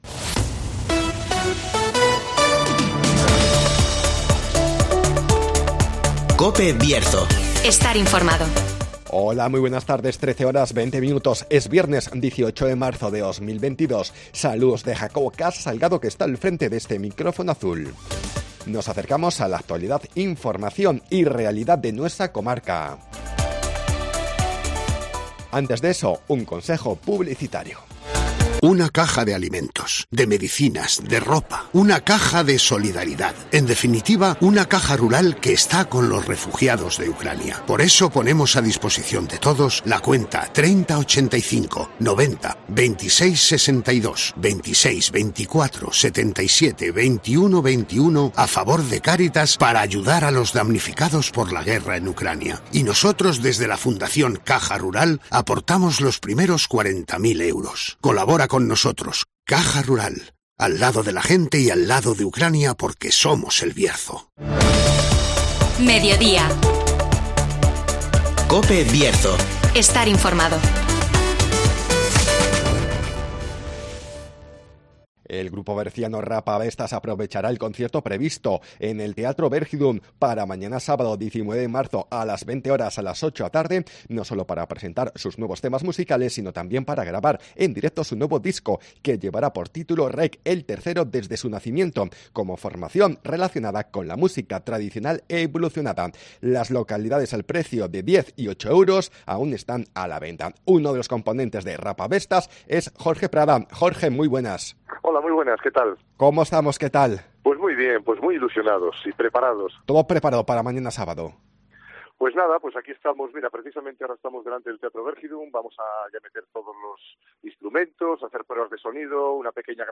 Programas Bierzo